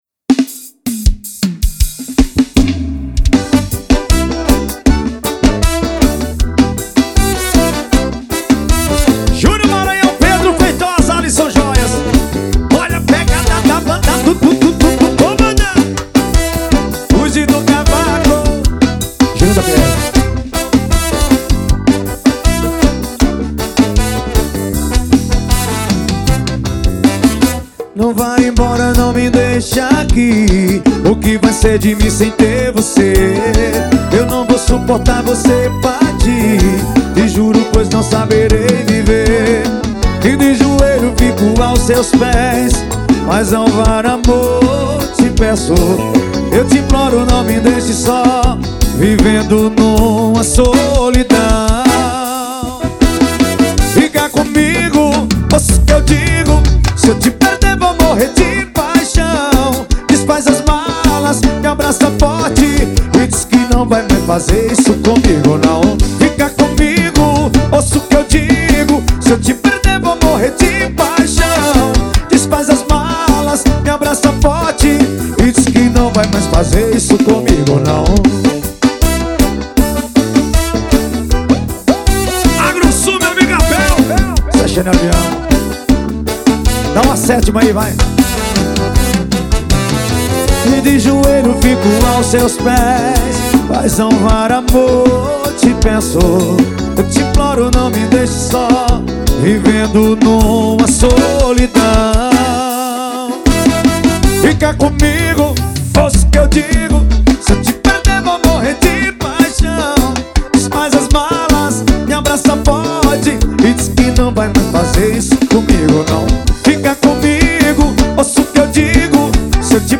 2024-02-14 18:42:52 Gênero: FORRO Views